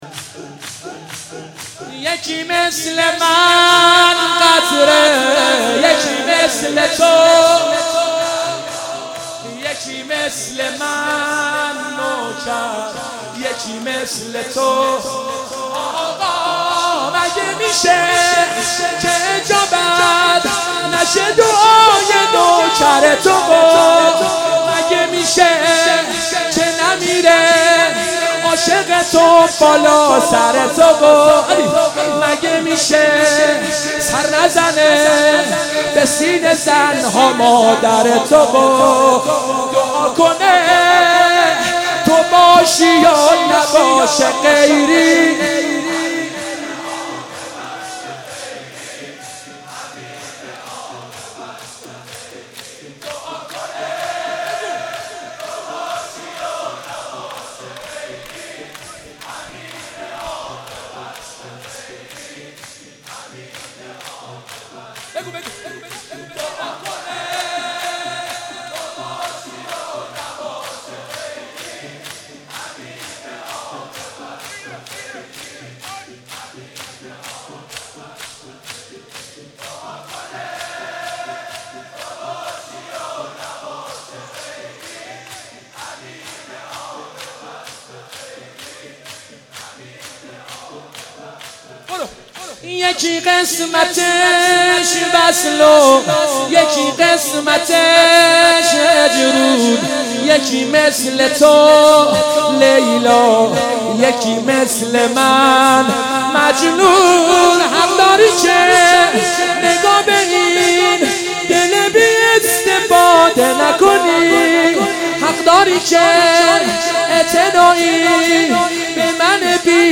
شب تاسوعا محرم95/هیئت خادم الرضا (ع) قم
شور/یکی مثل من